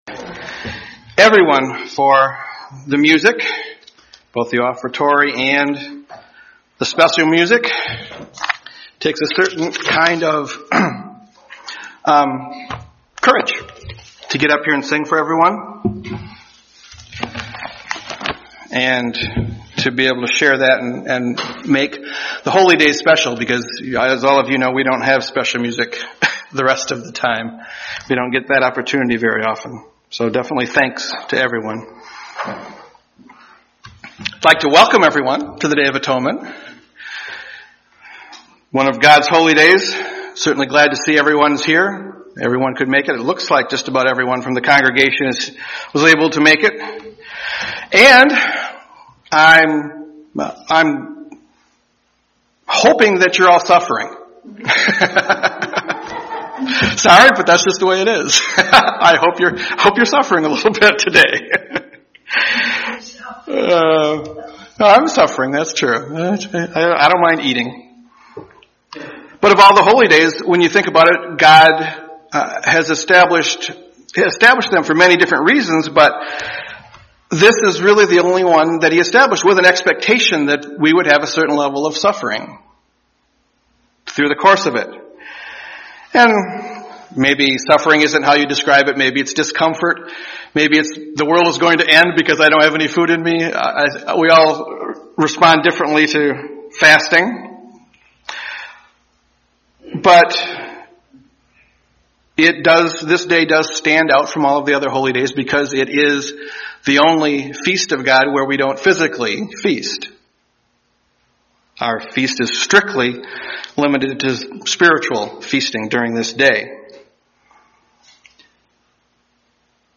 Given in Grand Rapids, MI
Holy Day Services Studying the bible?